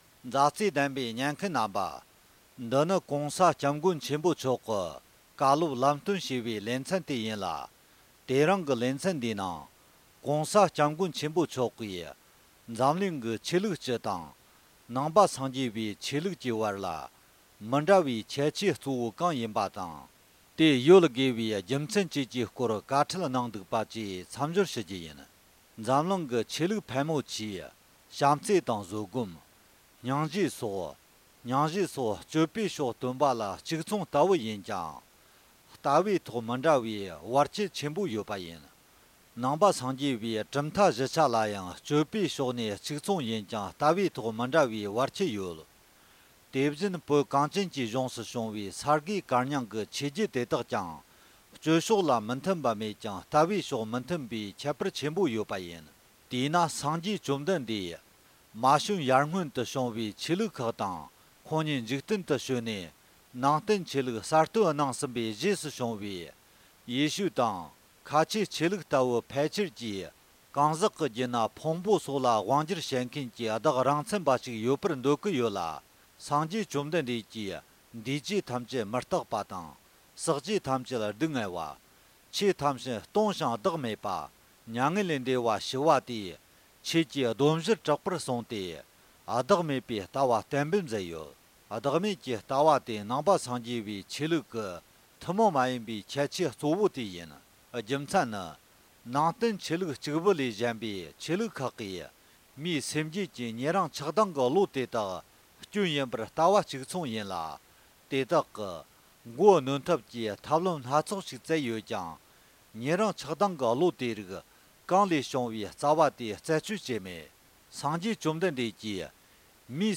༄༅། །༸གོང་ས་༸སྐྱབས་མགོན་ཆེན་པོ་མཆོག་གི་བཀའ་སློབ་ལམ་སྟོན་ཞེས་པའི་ལེ་ཚན་ནང་། འཛམ་གླིང་གི་ཆོས་ལུགས་སྤྱི་དང་ནང་ཆོས་དབར་མི་འདྲ་བའི་ཁྱད་ཆོས་གཙོ་བོ་གང་ཡིན་པ་དང་། དེ་ཡོད་དགོས་པའི་རྒྱུ་མཚན་སྐོར་བཀའ་གནང་བ་ཞིག་སྙན་སྒྲོན་ཞུ་རྒྱུ་ཡིན།།